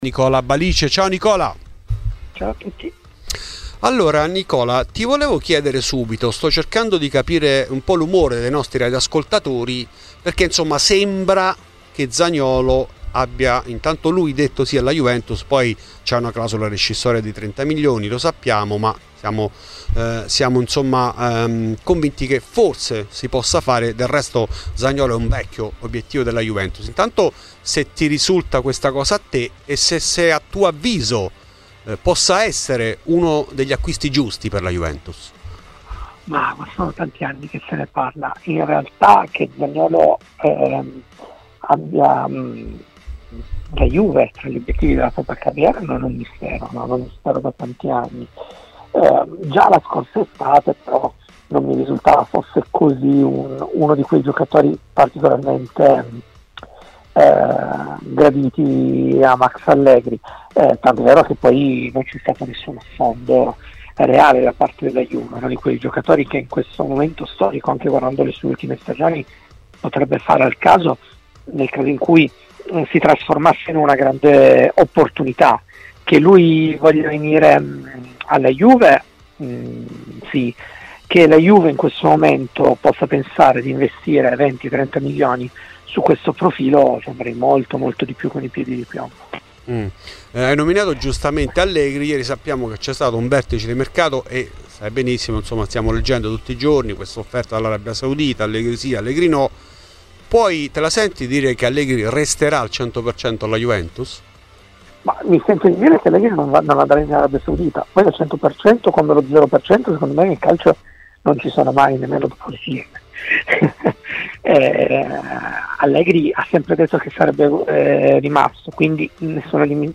In ESCLUSIVA ai microfoni di Fuori di Juve